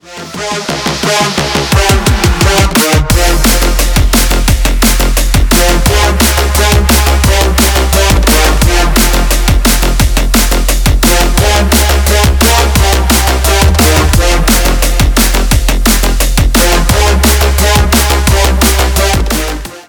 Электроника
клубные
без слов